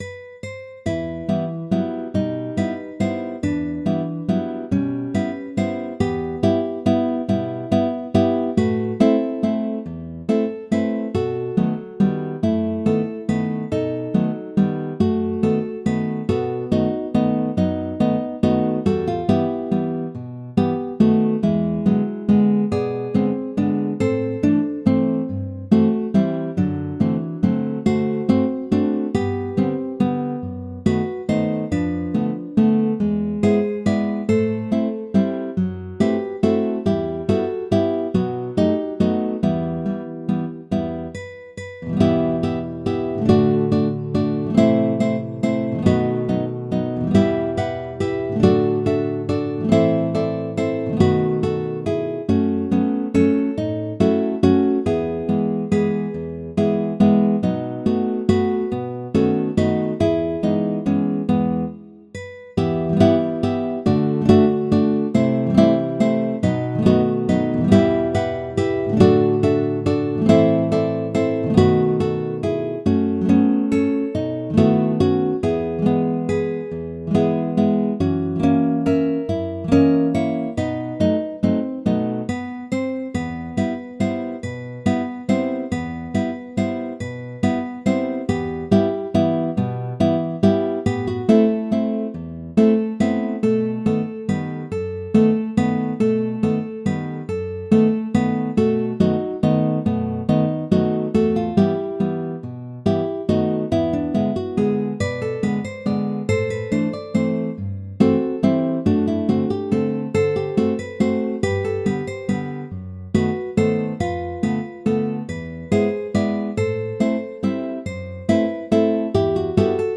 「MP3]はMIDIデータからサウンドフォントを利用して変換した音楽再生用データです。